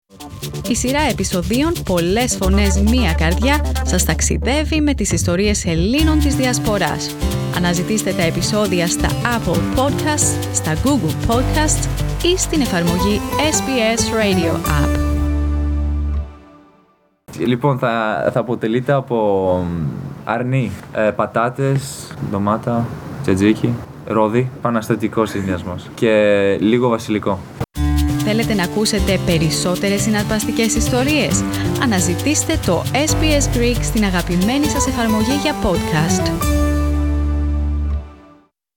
Πατήστε Play για να ακούσετε τον Στέφανο Τσιτσιπά να ανακοινώνει τα υλικά για το 'Tsitsipas Souvlaki'.